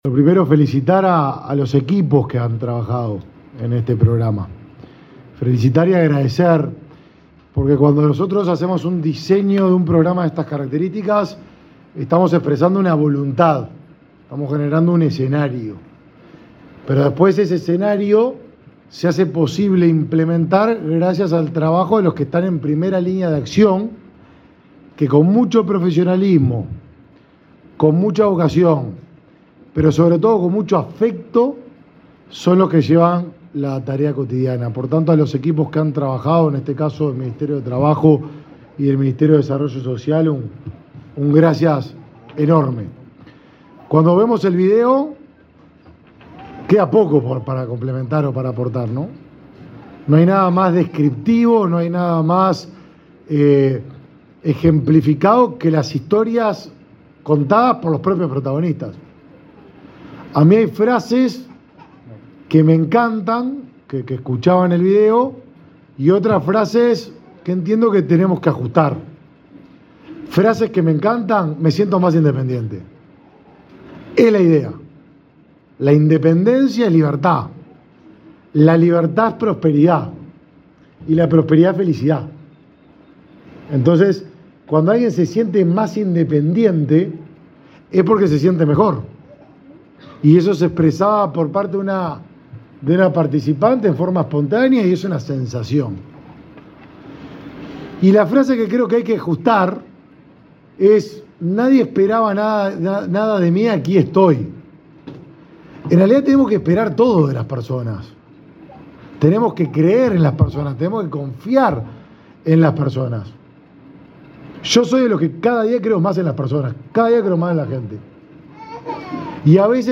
Palabra de autoridades en el acto de cierre del programa Accesos
El ministro de Desarrollo Social, Martín Lema, y su par de Trabajo, Pablo Mieres, participaron, este viernes 1.°, del cierre del programa Accesos,